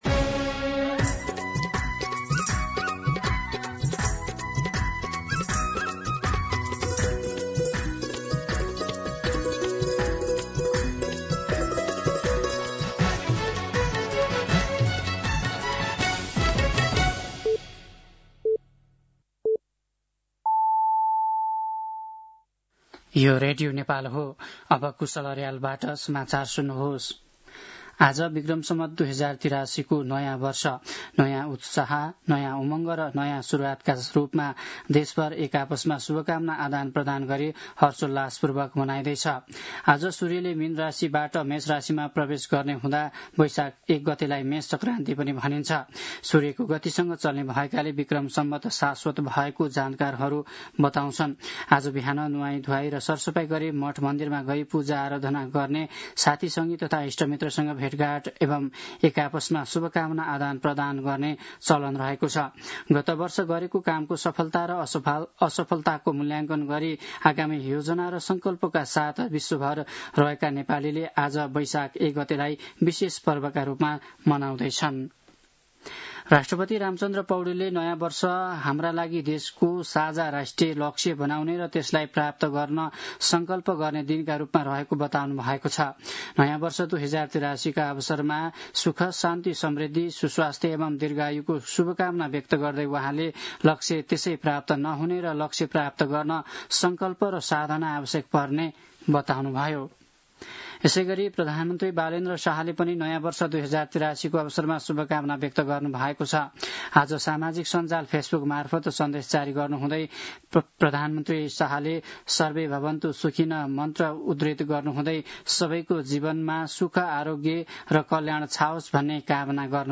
साँझ ५ बजेको नेपाली समाचार : १ वैशाख , २०८३
5-pm-news-2.mp3